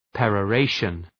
Προφορά
{,perə’reıʃən}
peroration.mp3